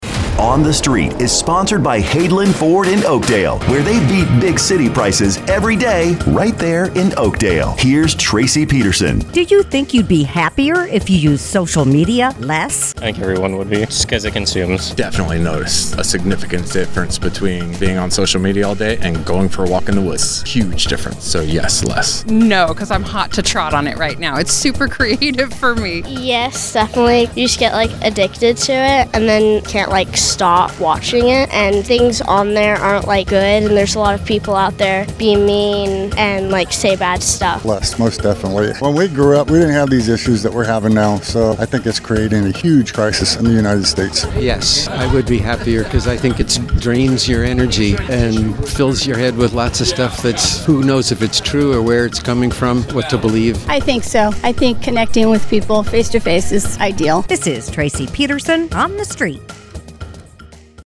asks Mother Lode residents, “Do you think you’d be happier if you used social media less?”